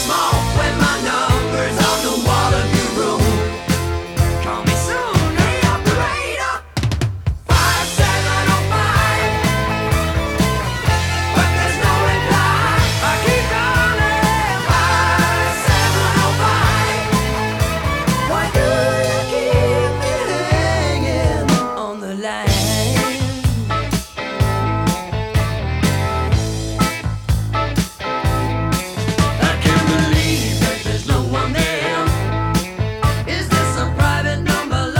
1978-01-26 Жанр: Рок Длительность